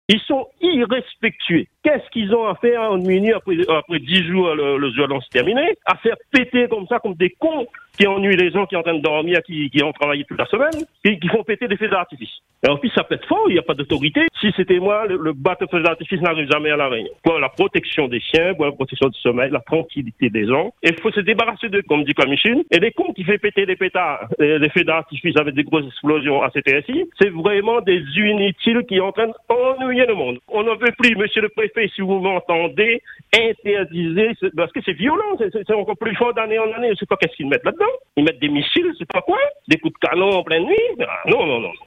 Pour ce riverain que vous allez entendre, la coupe est pleine.